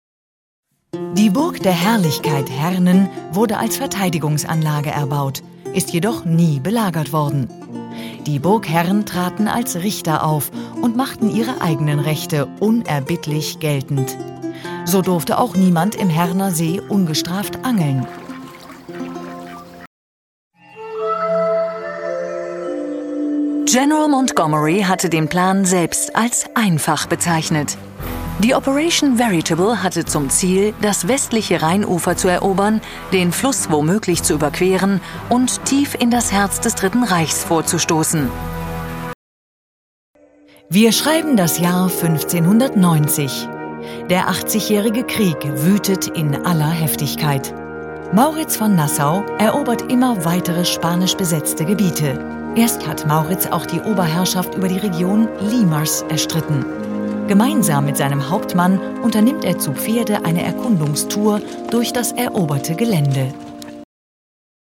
warm, friendly mid timbre German female voice over, wide range of voice age and tone of voice.
Sprechprobe: Sonstiges (Muttersprache):